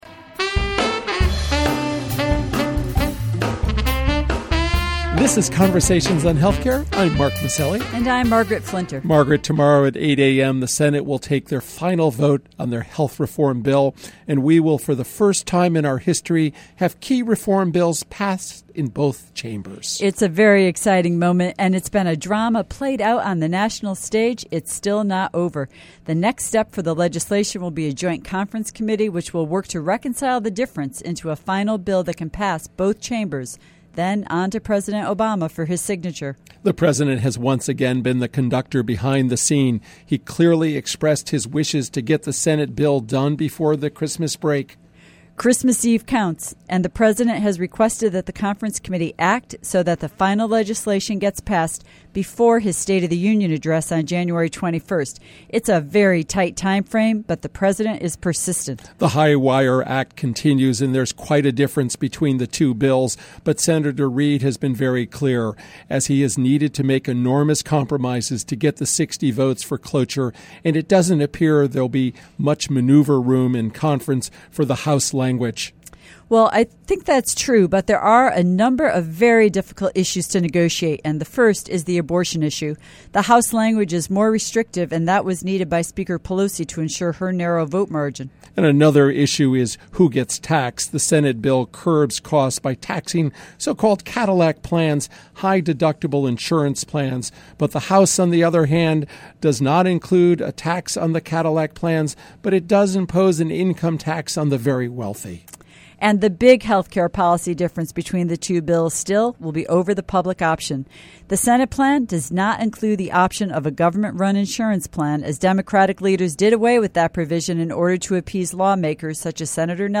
This week, Conversation on Health Care® talks with one of the country's leading economists, Dr. Karen Davis. As President of the Commonwealth Fund, a national philanthropy engaged in independent research on health and social policy issues, Dr. Davis and her team are highly influential researchers and thought leaders on issues of reform and innovation in health care.